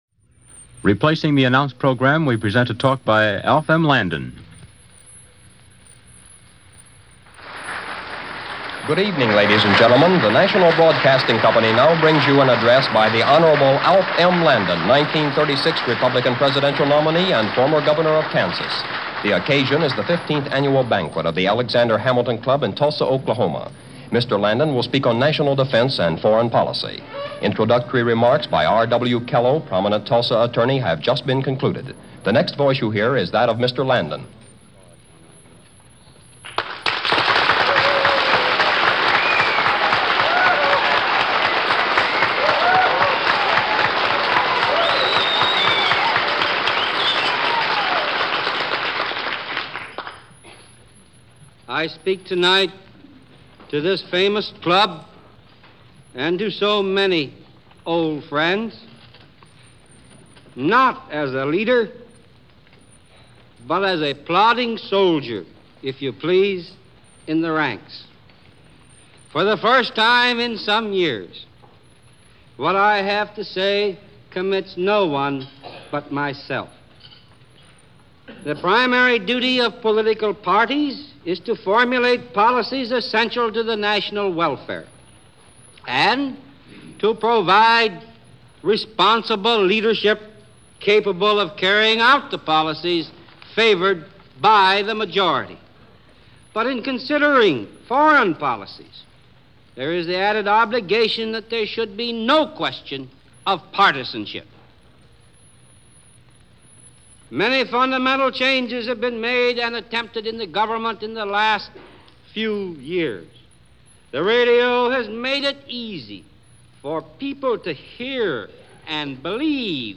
Alf Landon talks about Foreign Policy - at a dinner of the Alexander Hamilton Club in Tulsa Oklahoma - Jan. 11, 1941 - Paste Daily Reference Room